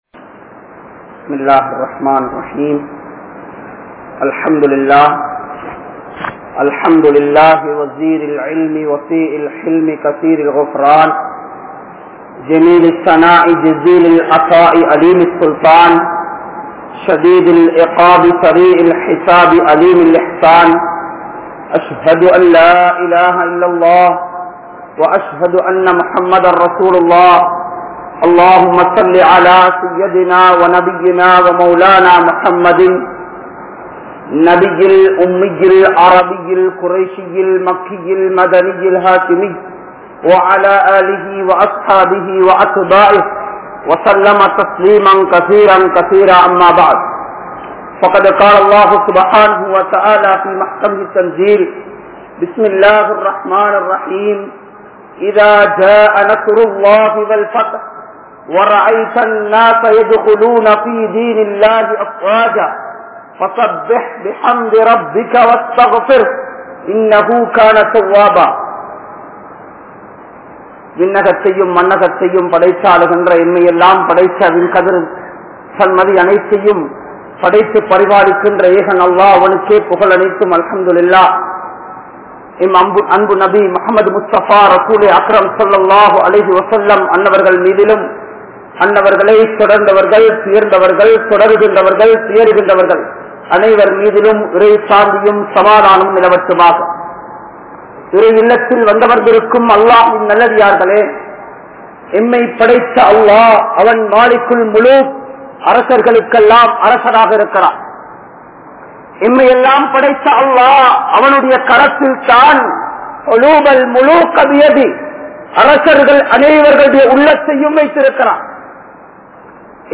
Muslimkale! Perumaiyadikaatheerhal (முஸ்லிம்களே! பெருமையடிக்காதீர்கள்) | Audio Bayans | All Ceylon Muslim Youth Community | Addalaichenai